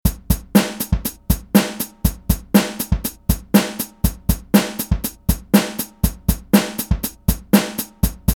Comment: This is another common drum beat variation found in pop and alternative music.